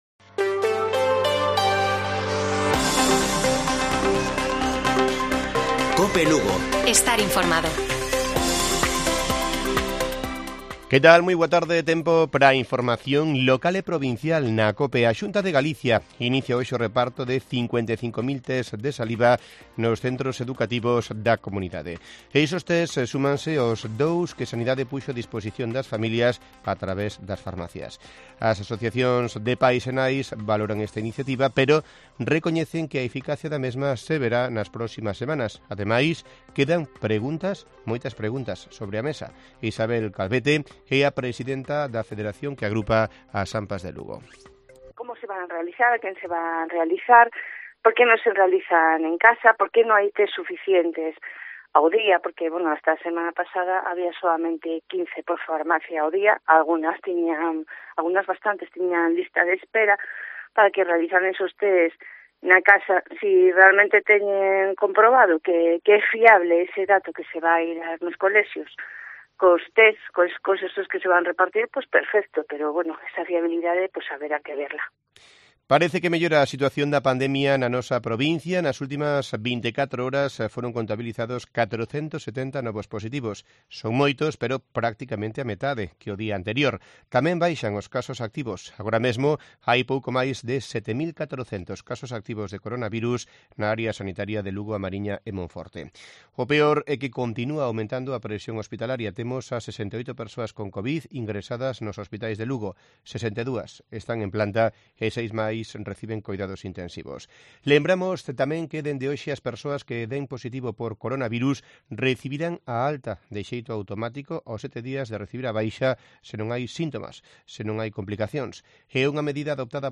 Informativo Mediodía de Cope Lugo. 17 de enero. 14:20 horas